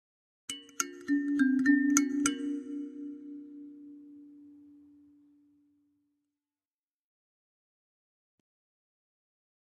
Kalimba, Accent, Type 3